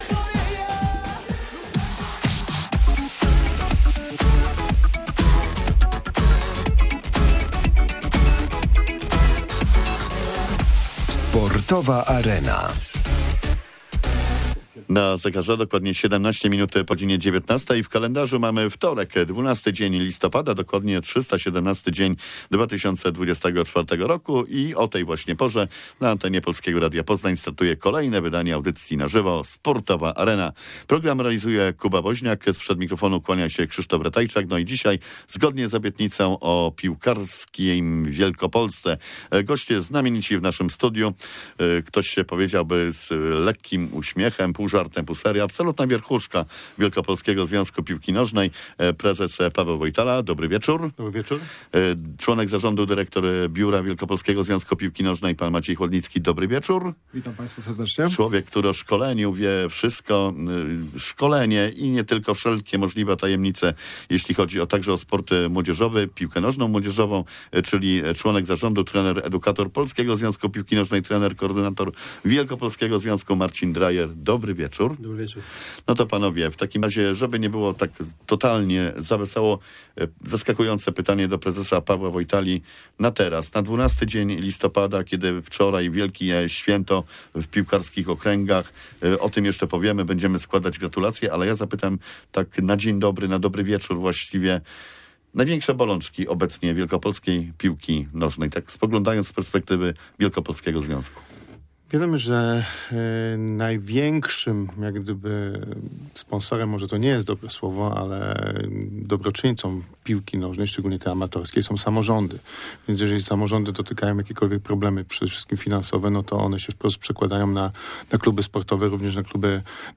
Sportowa Arena-emisja live we wtorek 12 listopada 2024, godz. 19.15. Goście z Wielkopolskiego Związku Piłki Noznej- prezes Paweł Wojtala